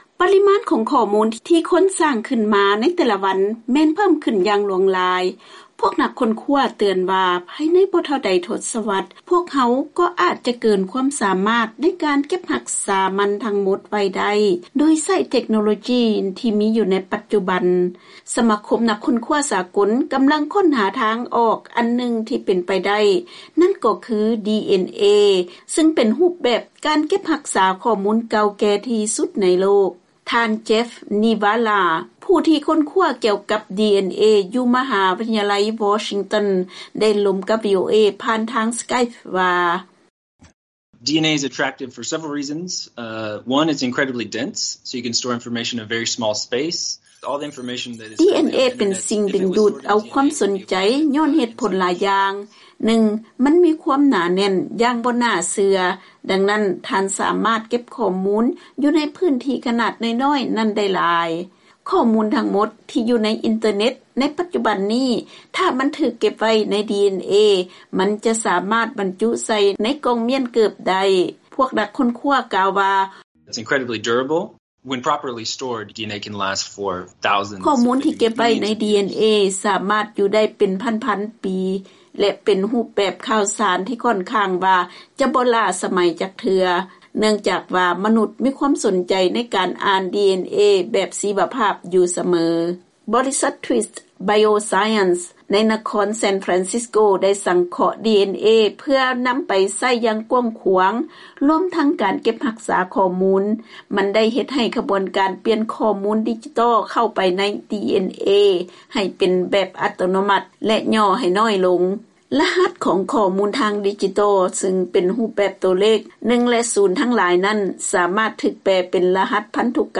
ເຊີນຟັງລາຍງານກ່ຽວກັບການນຳໃຊ້ ດີແອນເອເປັນບ່ອນເກັບຮັກສາຂໍ້ມູນທາງດິຈິຕອລໃນອະນາຄົດ